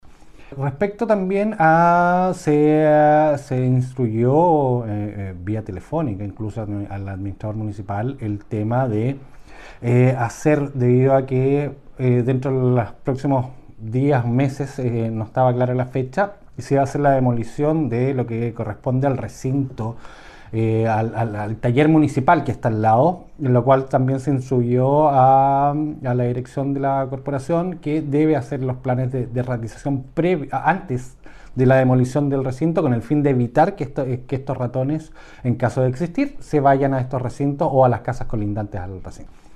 El personero además informó que se han adoptado medidas a modo de prevención, ante el inminente demolición que se va a realizar de una estructura colindante con el cesfam René Tapia, que corresponde actualmente a los talleres municipales, lo que podría incidir en la aparición de más vectores.